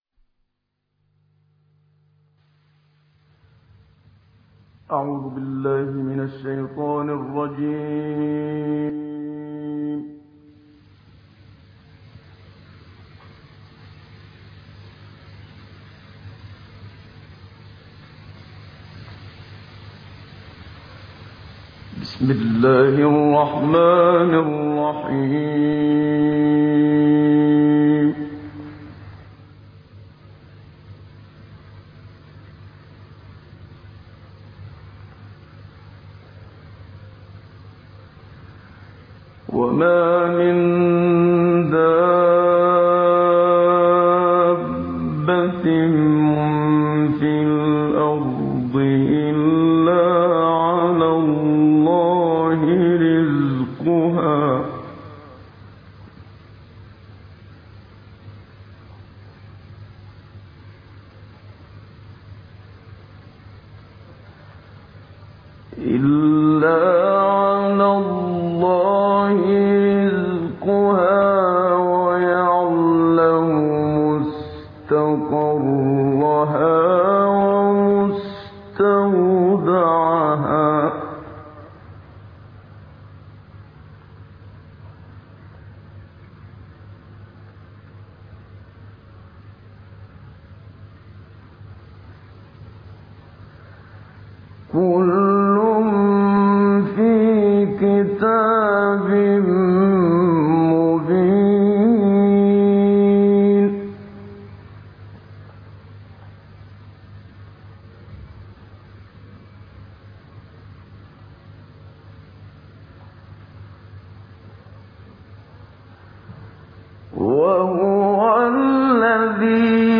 عنوان المادة 011 هود6-26 تلاوات نادرة بصوت الشيخ محمد صديق المنشاوي تاريخ التحميل السبت 11 نوفمبر 2023 مـ حجم المادة 13.46 ميجا بايت عدد الزيارات 102 زيارة عدد مرات الحفظ 61 مرة إستماع المادة حفظ المادة اضف تعليقك أرسل لصديق